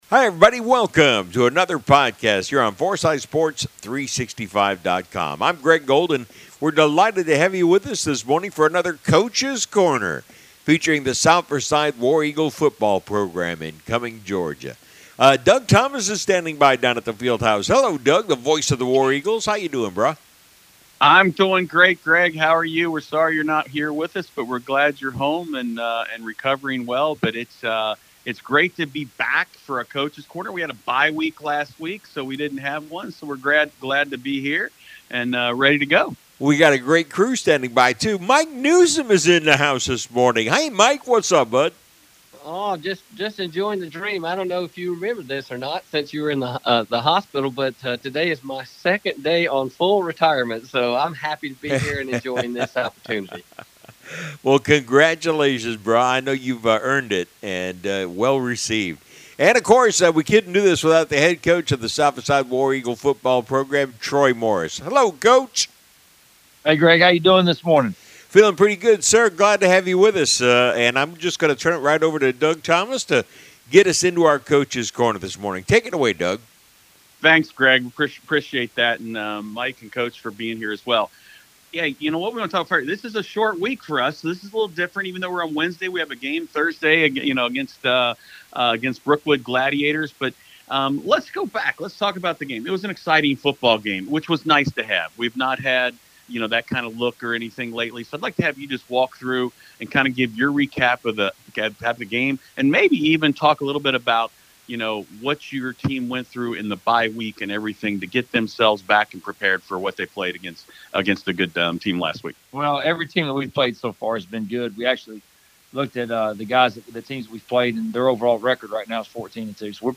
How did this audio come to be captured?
Here’s the podcast, another one take wonder from Forsyth Sports 365.